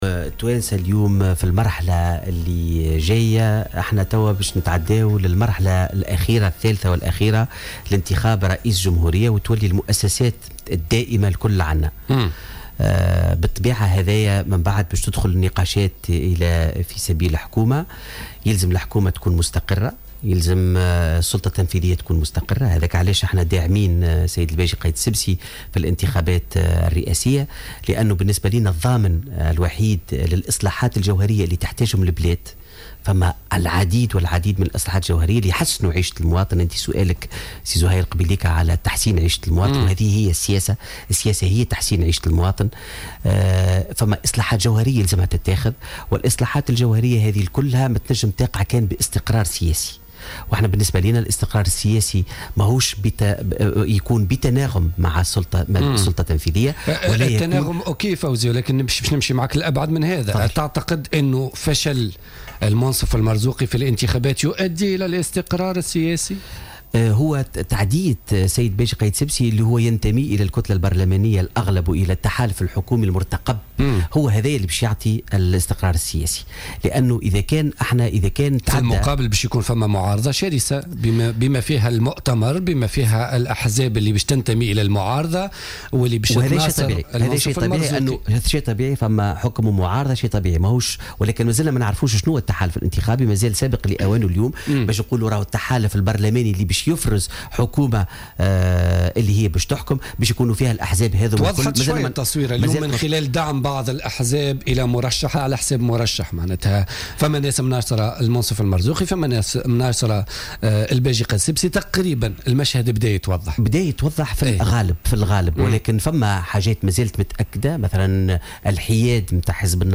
اعتبر فوزي عبد الرحمان ممثل حزب آفاق تونس، ضيف برنامج "بوليتيكا" اليوم الثلاثاء أن فوز المرشح المنصف المرزوقي في الدور الثاني للانتخابات الرئاسية لن يساعد على الاستقرار السياسي.